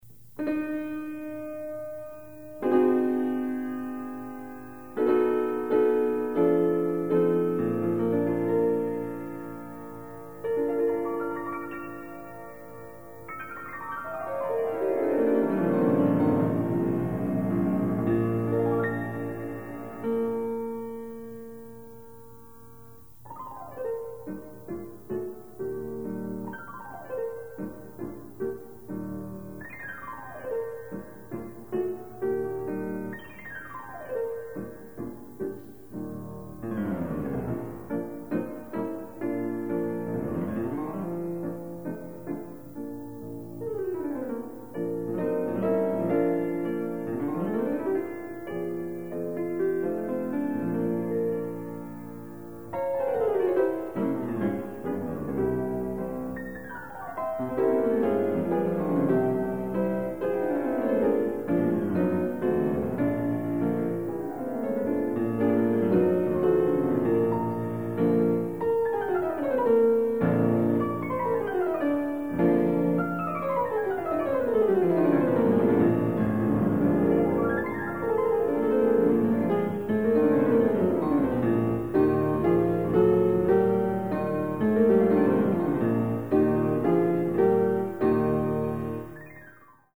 Additional Date(s)Recorded September 11, 1977 in the Ed Landreth Hall, Texas Christian University, Fort Worth, Texas
Etudes
Short audio samples from performance